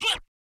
BwuttVox_JJ.wav